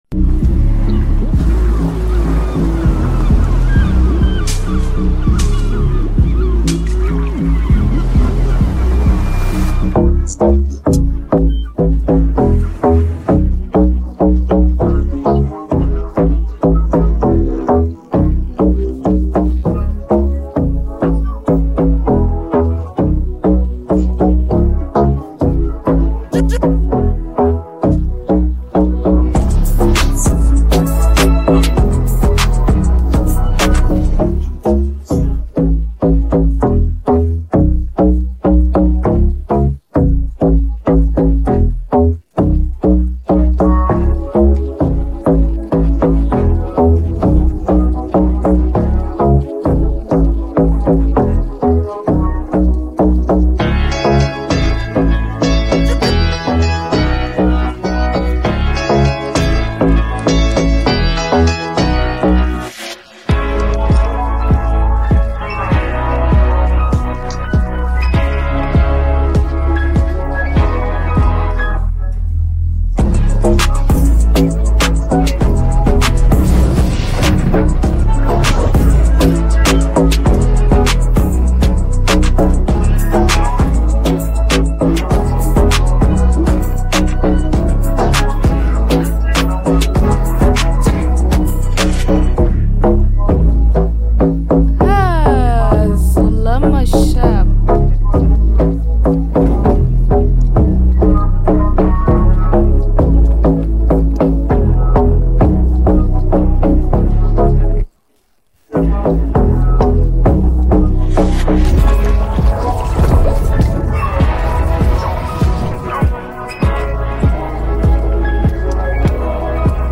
dancehall beats
Reggae